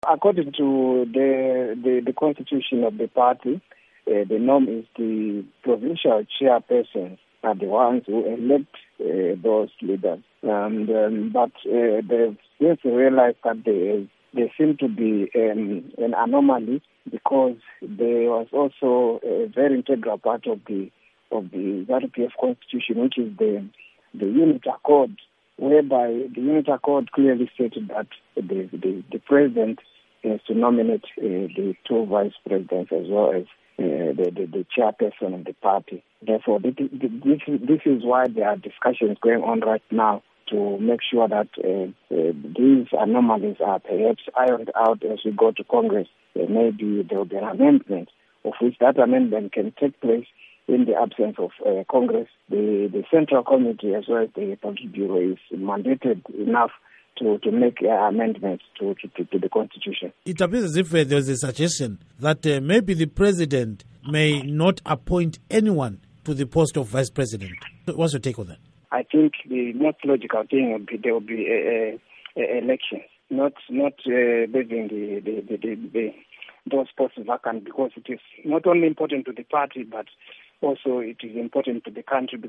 Political commentator and Zanu PF activist
Interview